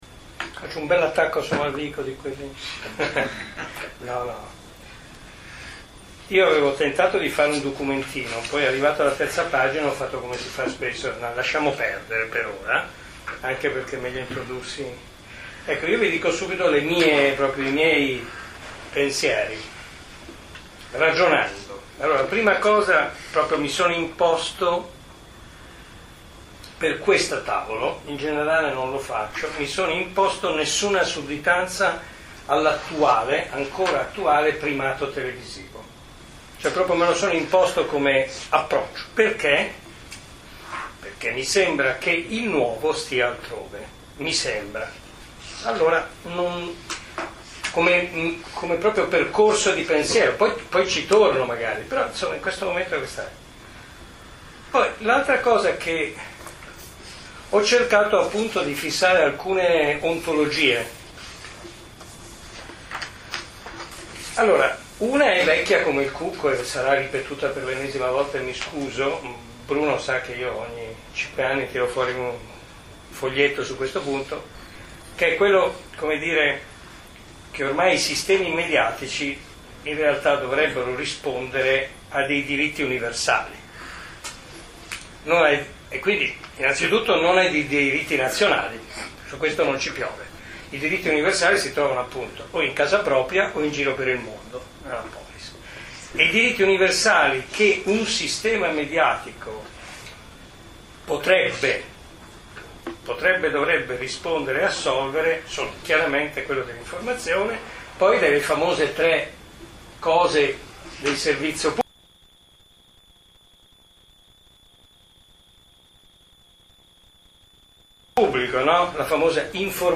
Seminario di Infocivica in collaborazione con Globus et Locus Milano - 15 gennaio 2009